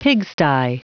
Prononciation du mot pigsty en anglais (fichier audio)
Prononciation du mot : pigsty